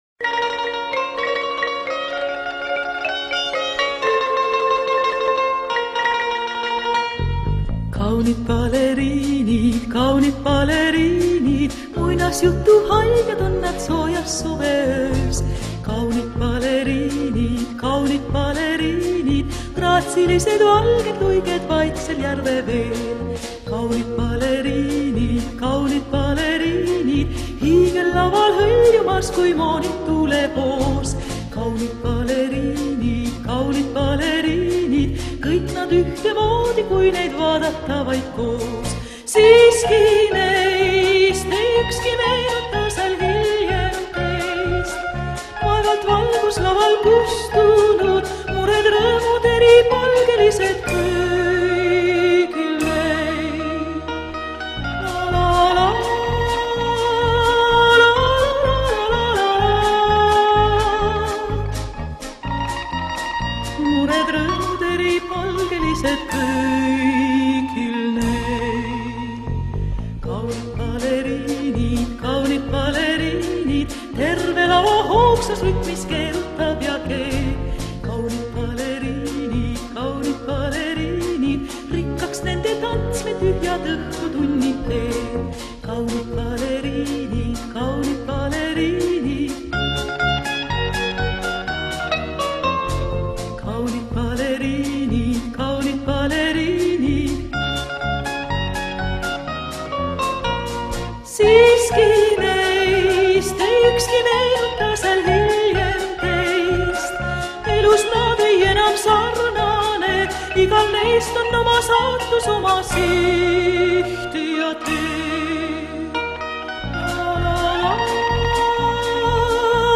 меццо-сопрано